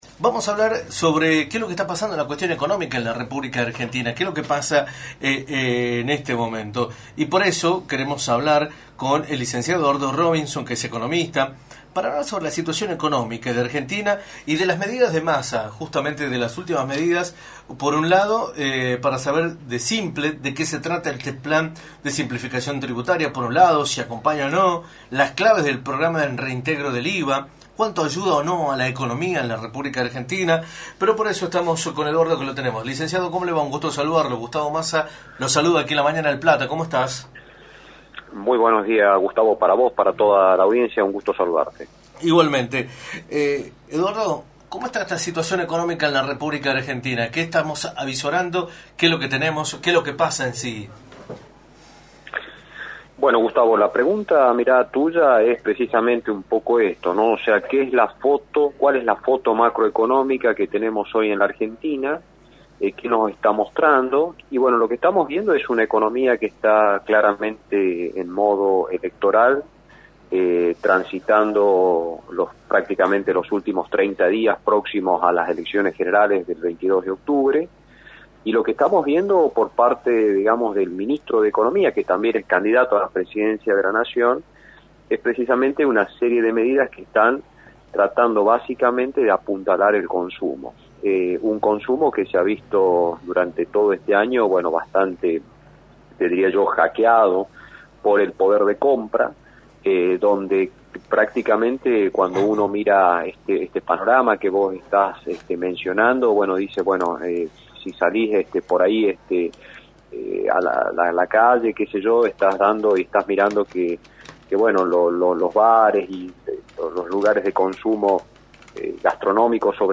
en entrevista para “La Mañana del Plata”, por la 93.9.